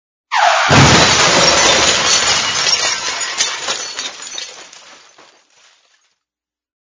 SONIDOSCAR CRASH
Ambient sound effects
Sonidoscar_crash.mp3